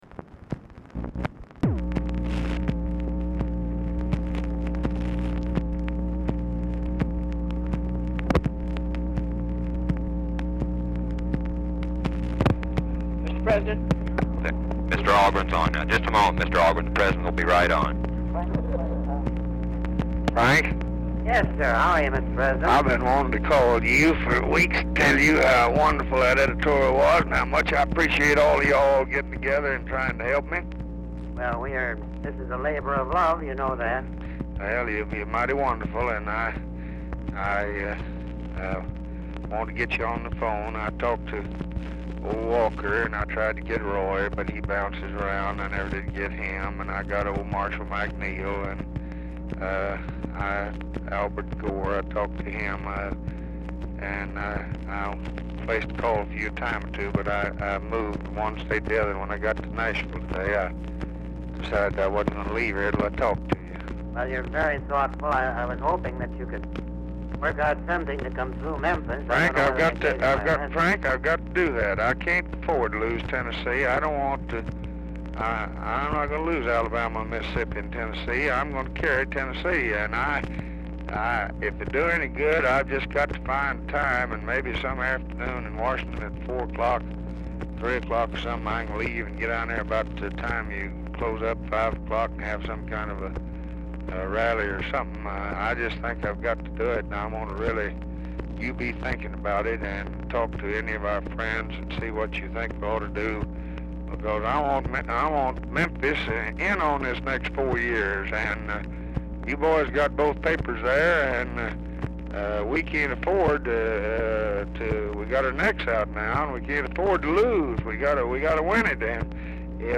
POOR SOUND QUALITY
Format Dictation belt
Specific Item Type Telephone conversation